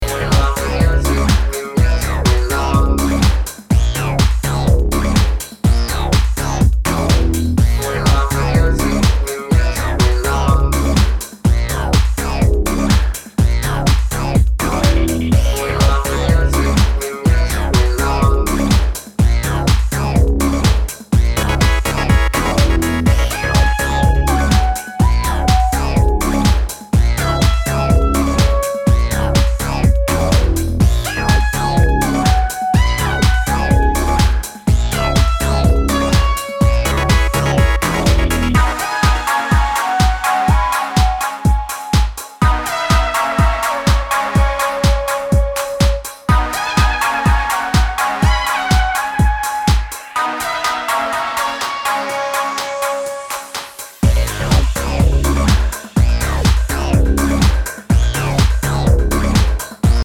vocoder vocals, an electro-funk bounce